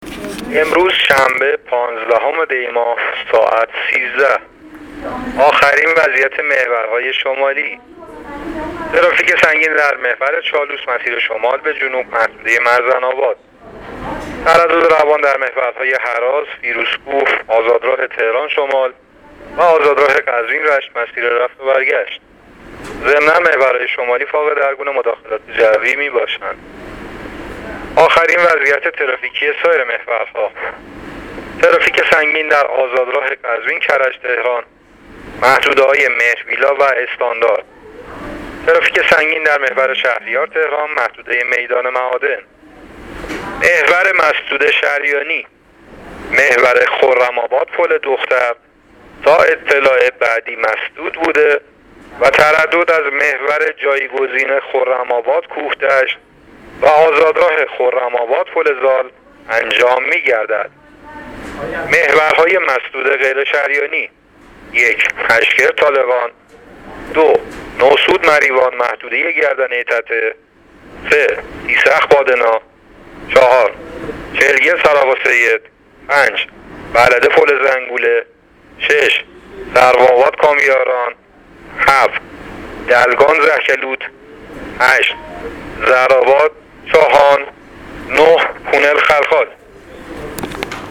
گزارش رادیو اینترنتی از آخرین وضعیت ترافیکی جاده‌ها تا ساعت ۱۳ پانزدهم دی؛